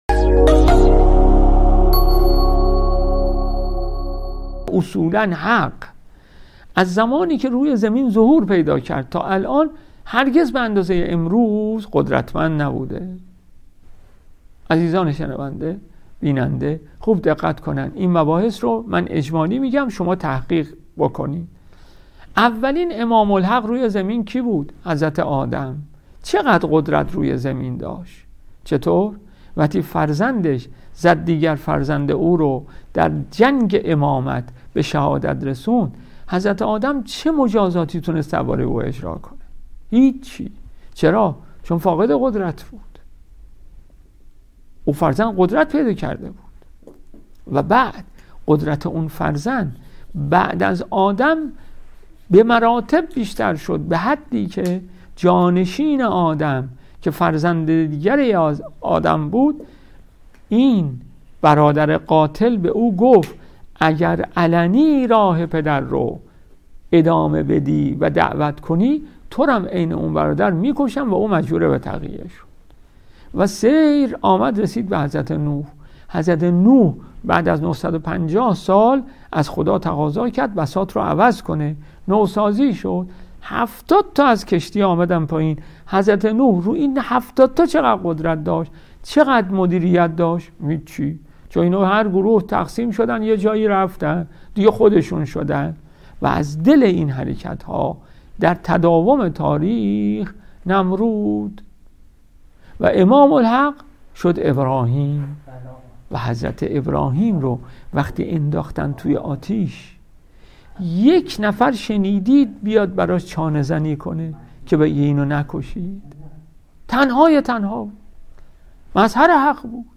صوت سخنرانی‌ها| قدرت جبهه‌ی‌حق از عصر حضرت آدم تا عصر امامین انقلاب